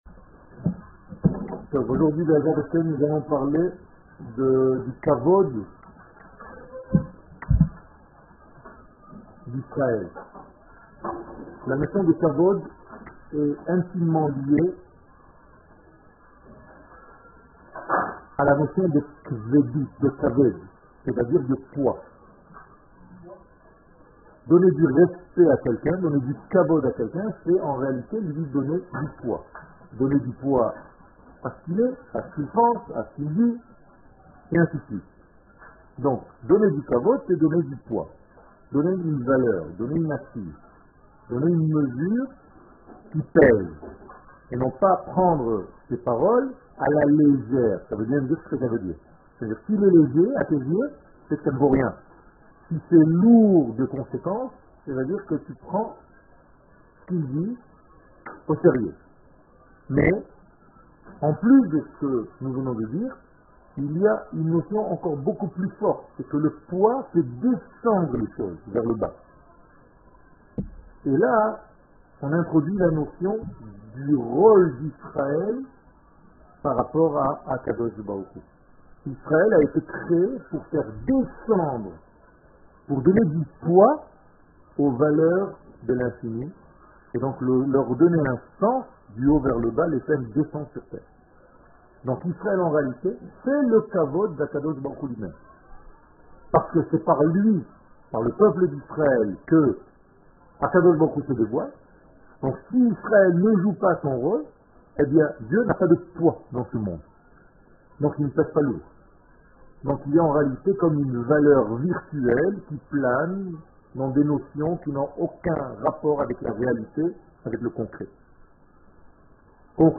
Emouna- La Kavod de Am Israel Eretz Israel שיעור מ 24 יוני 2014 57MIN הורדה בקובץ אודיו MP3 (26.11 Mo) הורדה בקובץ אודיו M4A (7.19 Mo) TAGS : Secrets d'Eretz Israel Torah et identite d'Israel שיעורים קצרים